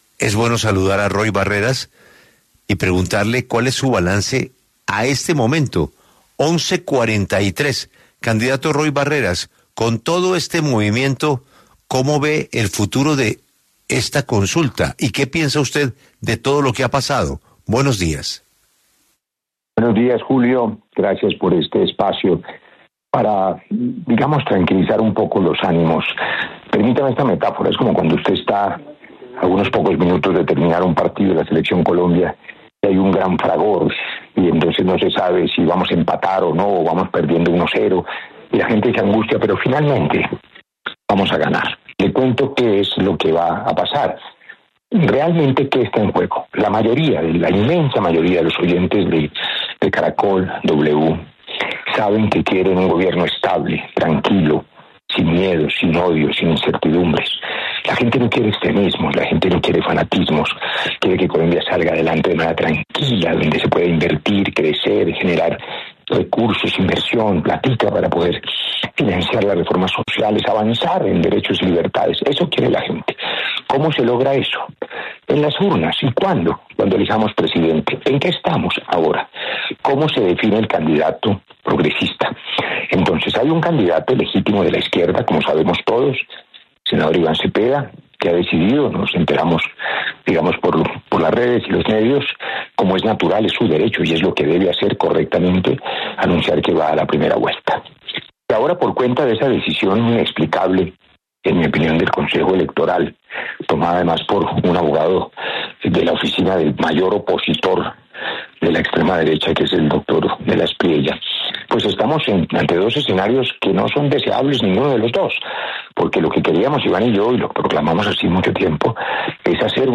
El precandidato presidencial habló en 6AM W sobre el ‘Frente por la Vida’ y aseguró que no permitirá que la derecha gane por ‘W’.
En diálogo con 6AM W, el precandidato presidencial Roy Barreras ratificó su voluntad de participar en la consulta del ‘Frente por la Vida’ el próximo 8 de marzo, a pesar de que el Consejo Nacional Electoral (CNE) revocara la inscripción de Iván Cepeda.